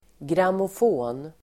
Uttal: [gramof'å:n]